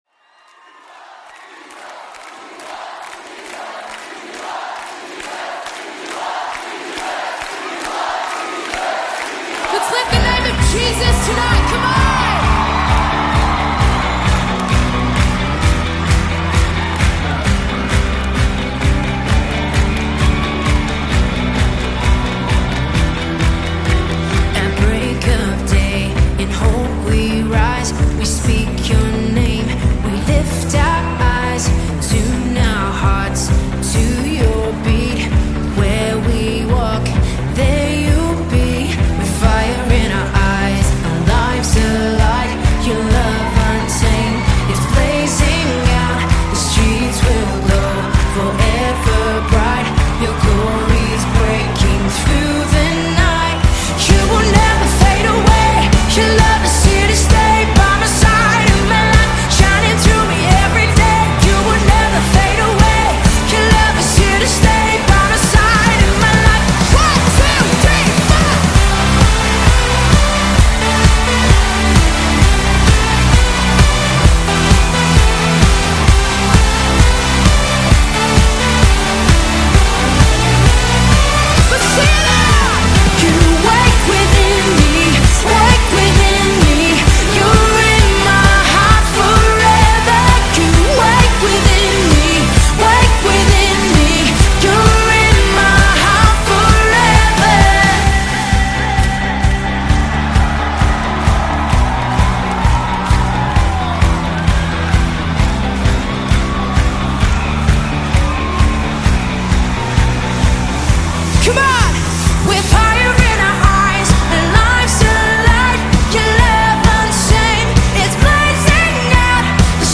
740 просмотров 244 прослушивания 12 скачиваний BPM: 131